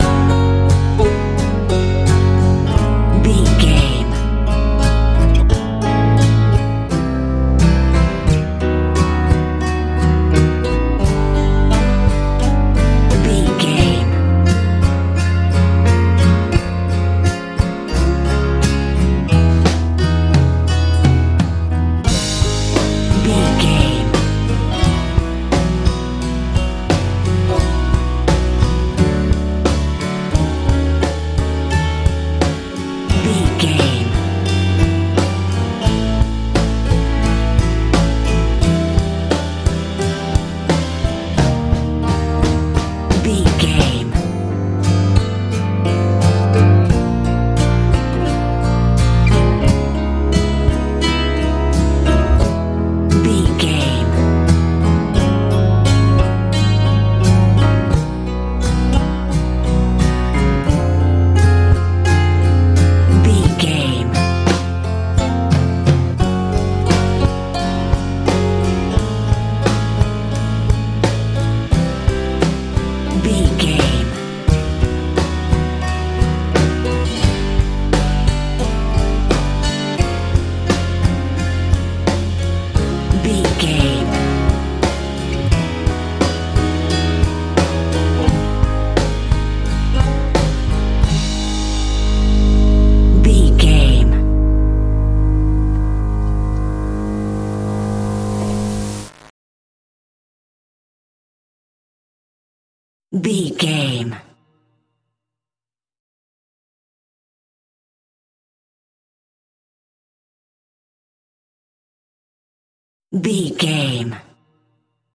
country pop ballad
Ionian/Major
B♭
sweet
piano
acoustic guitar
bass guitar
drums
driving
happy
lively